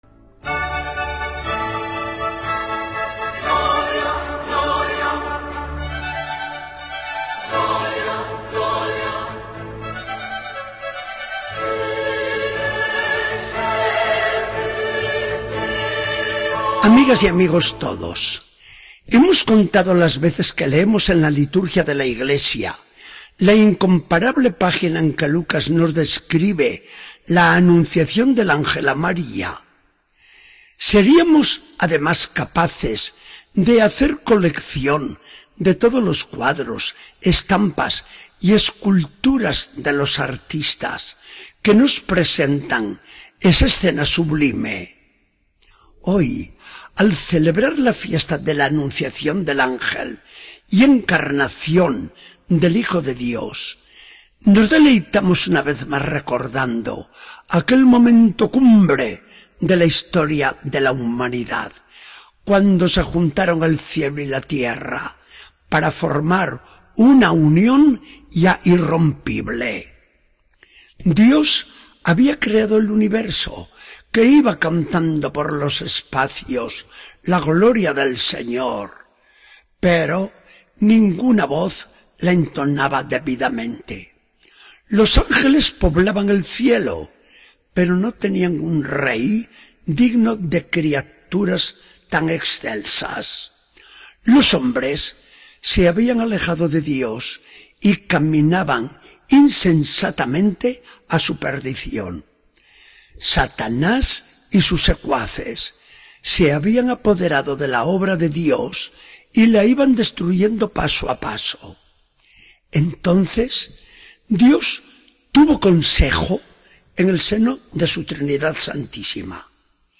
Charla del día 25 de marzo de 2014. Del Evangelio según San Lucas 1, 26-38.